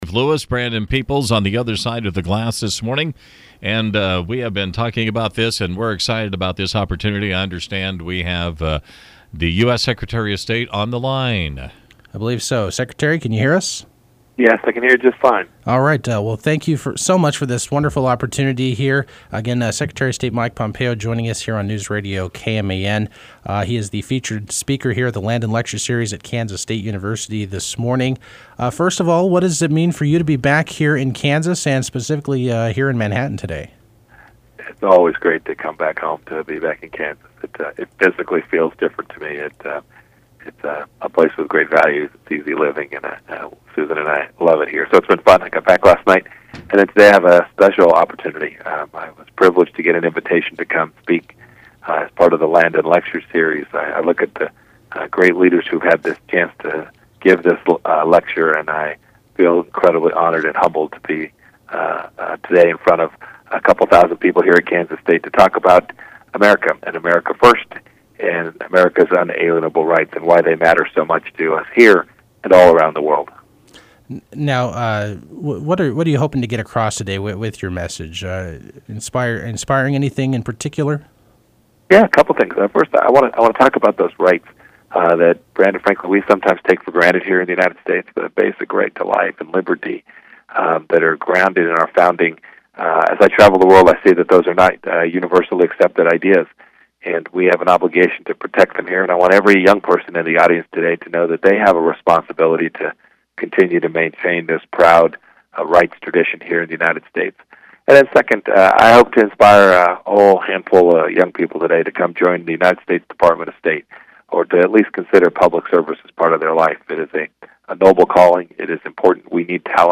The interview coincided with Pompeo’s visit Friday to McCain Auditorium for the 190th K-State Landon Lecture.